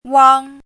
chinese-voice - 汉字语音库
wang1.mp3